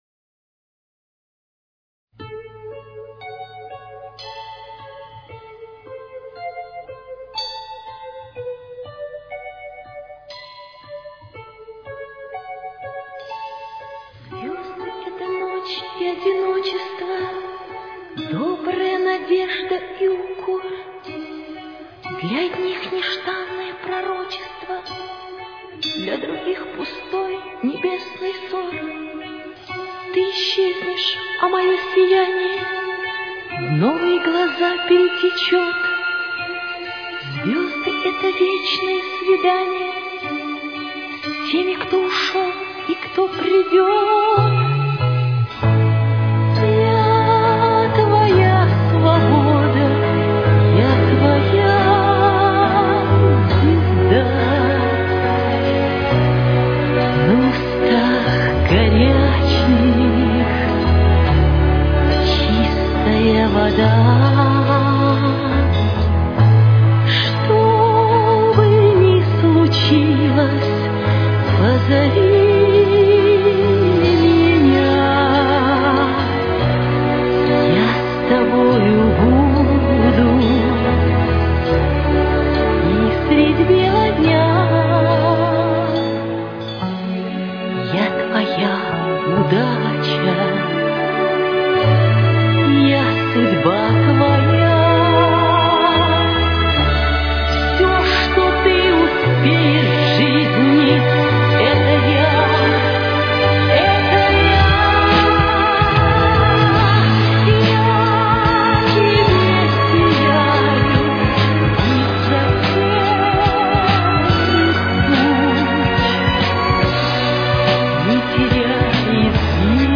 Темп: 71.